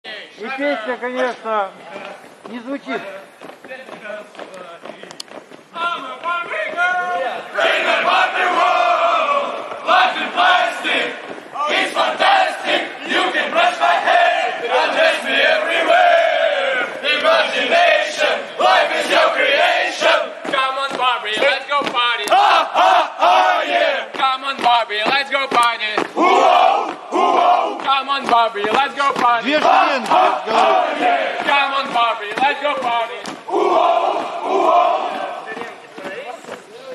Курсанты с военной кафедры. Первые тренировки марша с песней.